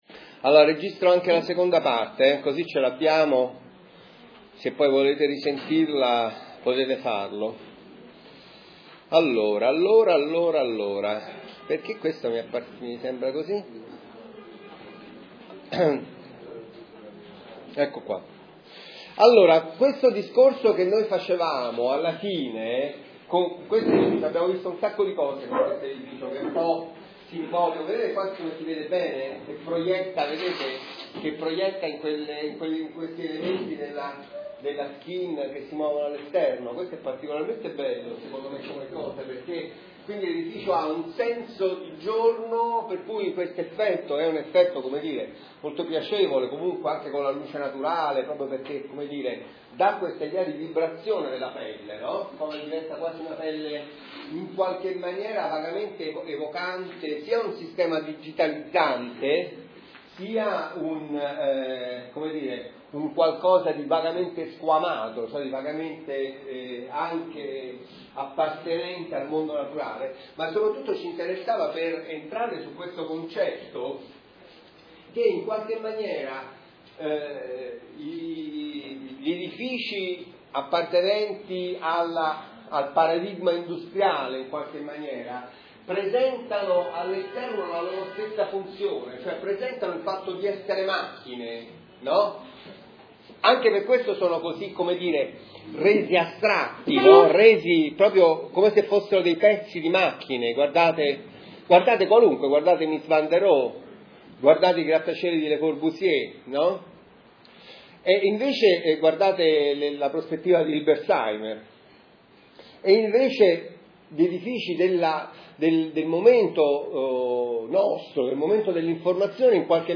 Lezione laboratorio